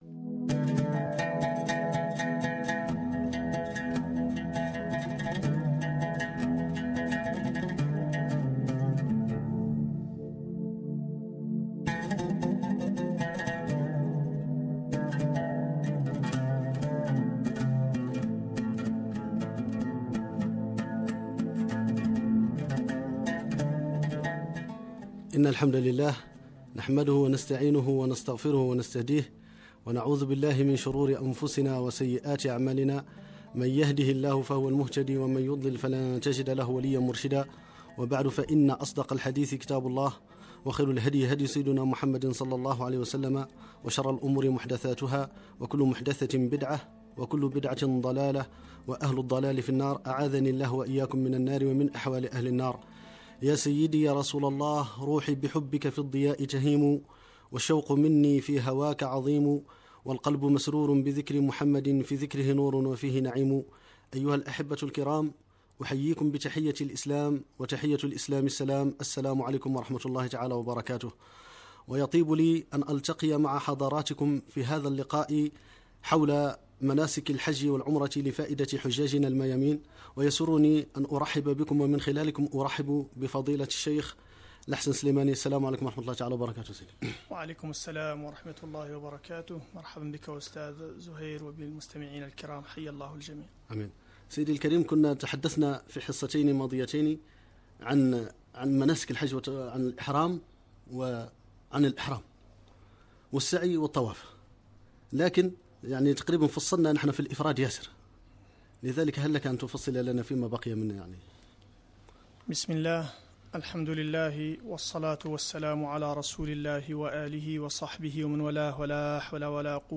ندوة دينية لفائدة الحجاج حول مناسك الحج
البرنامج الإذاعي: فضاء الجمعة